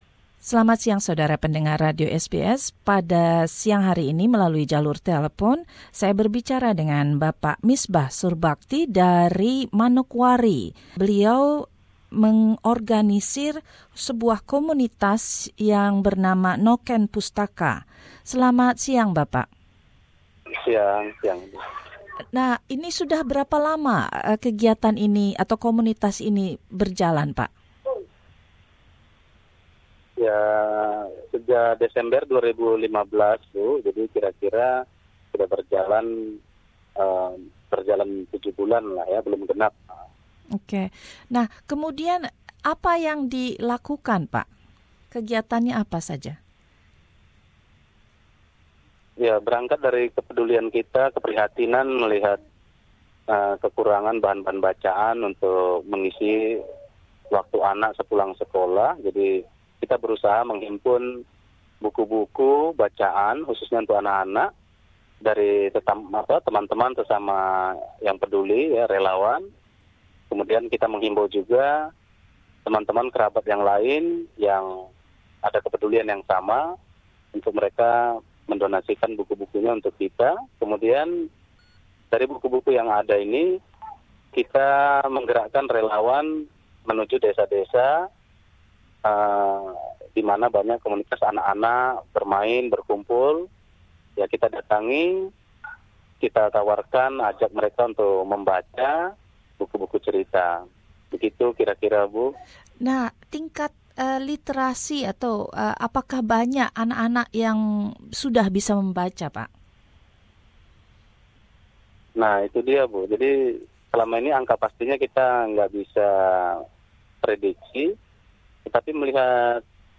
Bagaimana antusiasme anak-anak dan masalah yang dihadapi oleh para relawan, Ia menceritakan dalam wawancara ini.